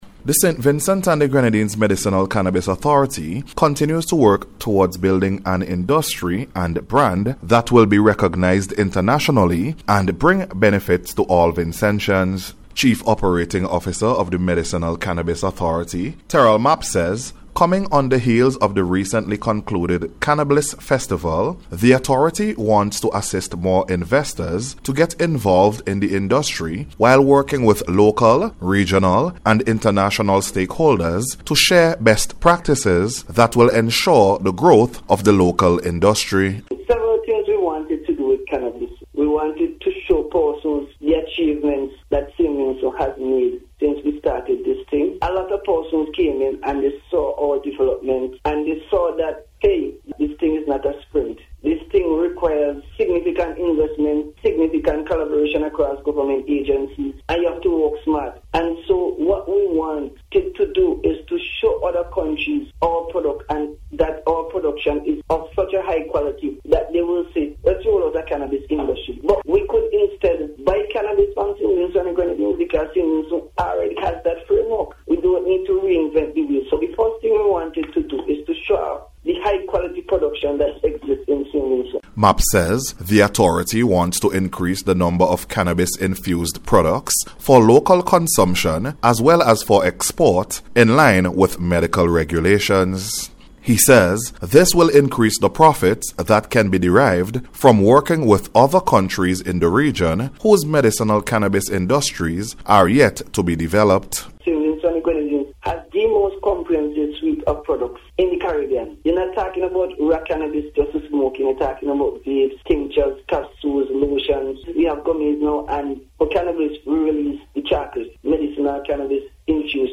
SVG-CANNABIS-FUTURE-EXPANSION-REPORT.mp3